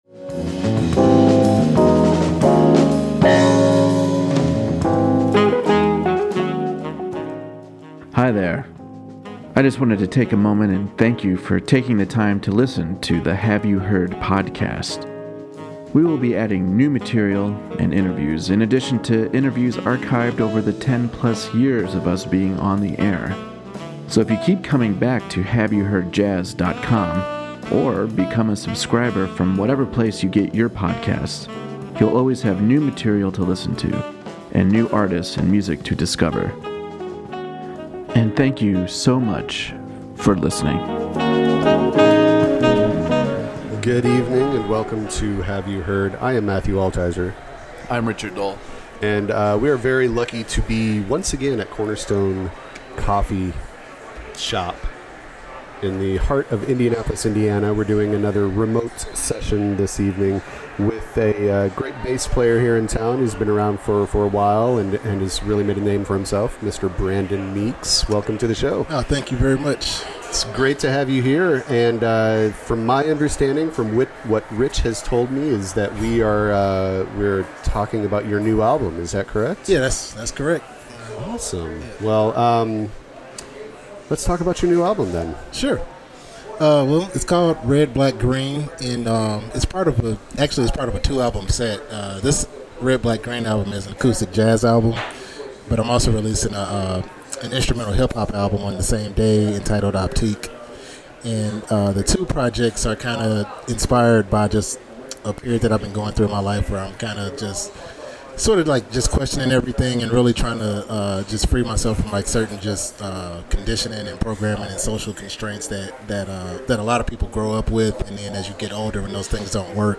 We recorded this at the Cornerstone Coffee Shop (54th & College Ave) in Broad Ripple, so there is plenty of ambient noise. The music is unaffected by the ambient noise. This album is a great balance of beautiful ballads and hard hitting straight ahead jazz.
Enjoy the conversation and the music!